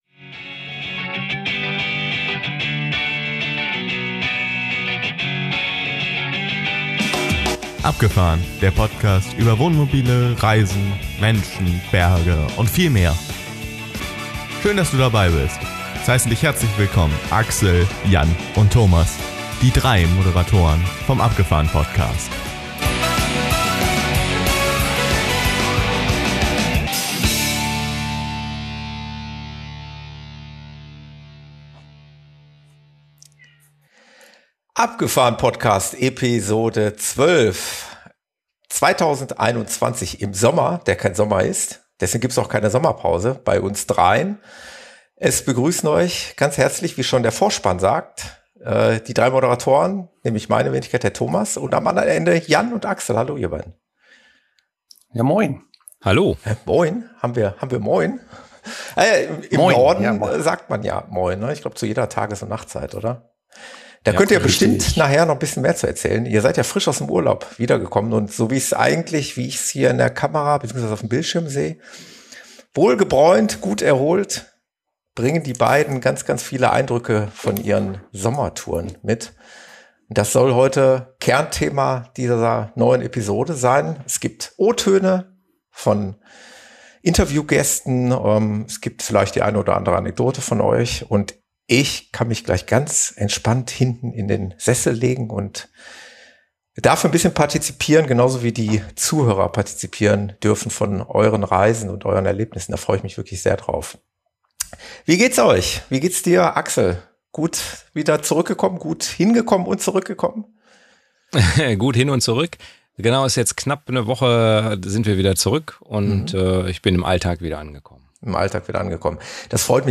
Zwei der drei Moderatoren müssen die Sommerferien in den Schulferien verbringen.